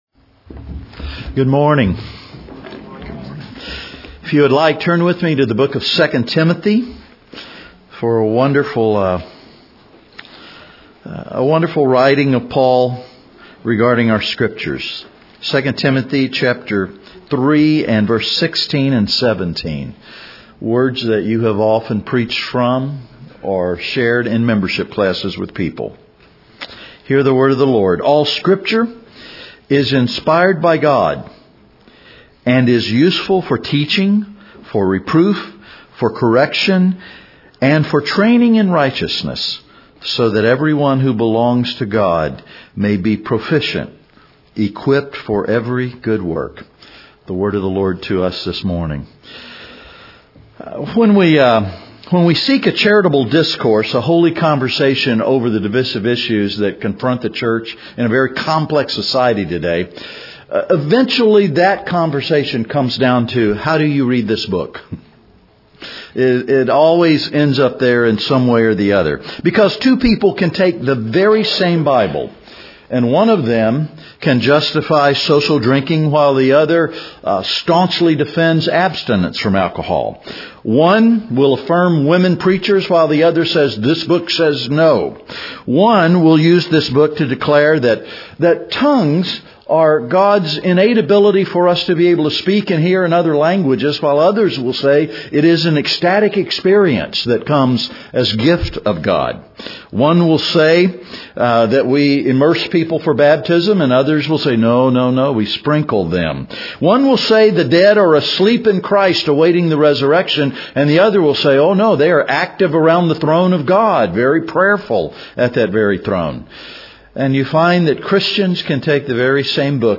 EuNC LC 2013 - Wednesday, 30 January 2013 - Morning Devotional
European Nazarene College Leadership Conference 2013 - Wednesday Morning Devotional